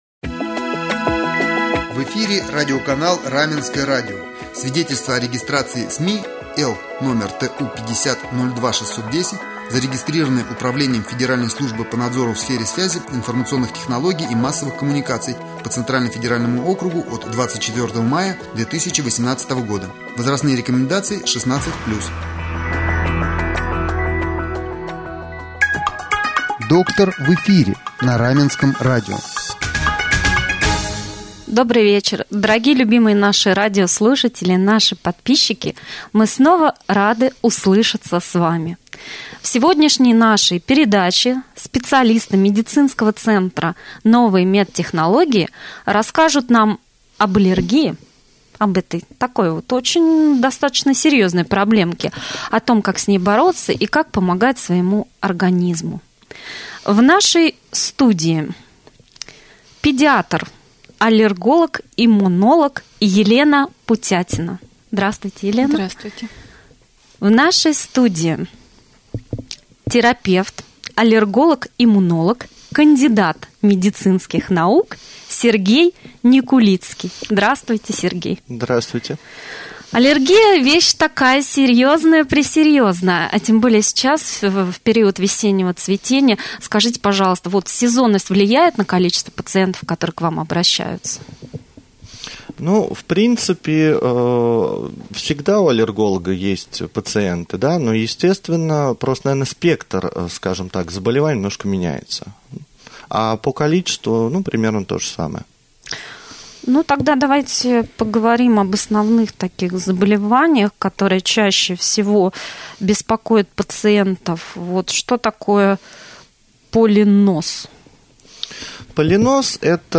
Доктора рассказали об одном из современных и эффективных методах лечения — аллергенспецифической иммунотерапии.